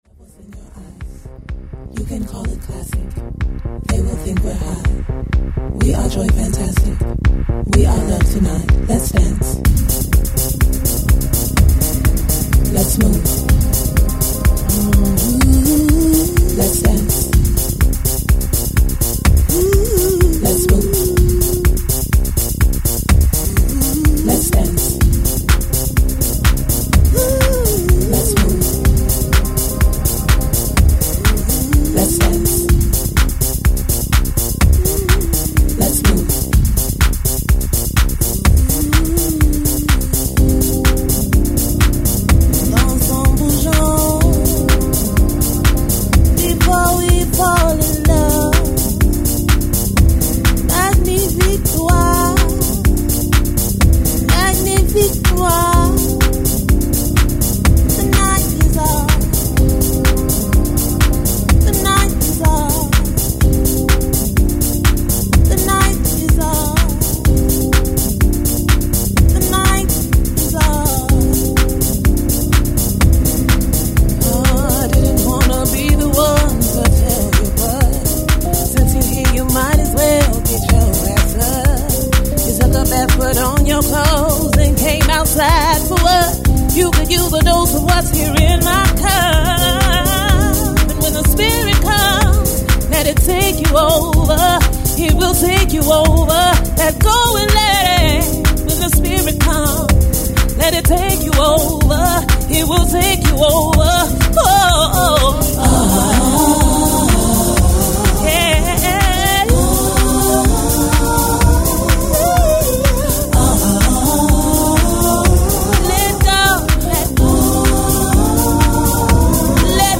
B1: Original Vocal